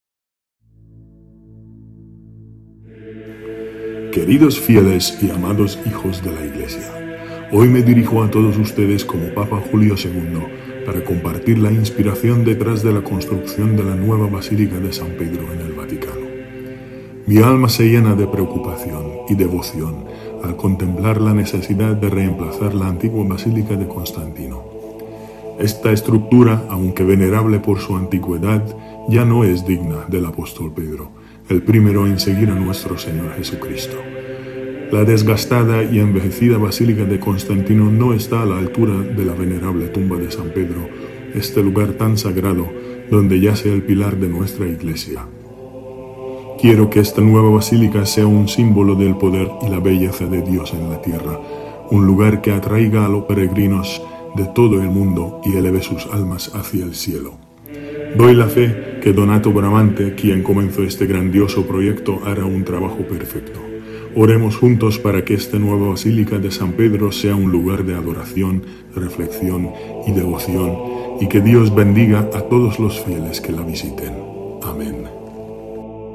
Papa-Julio-with-music.mp3